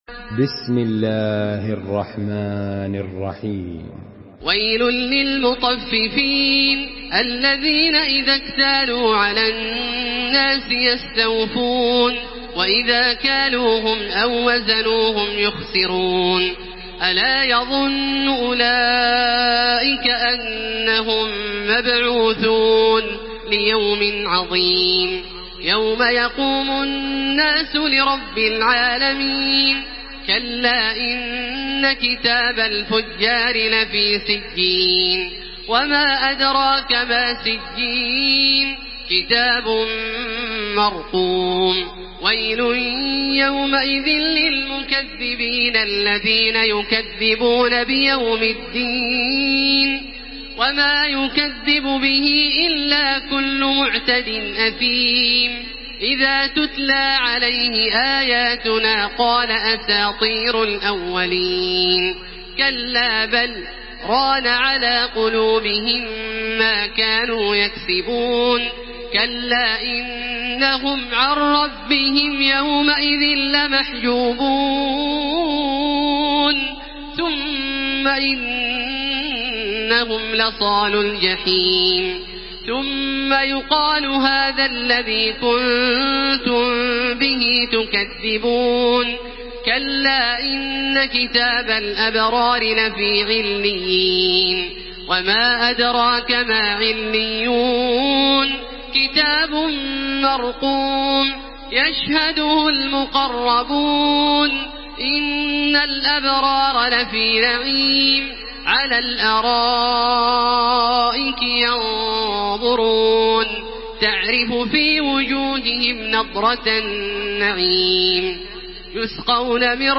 Surah Müteffifin MP3 in the Voice of Makkah Taraweeh 1433 in Hafs Narration
Murattal